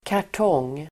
Uttal: [kar_t'ång:]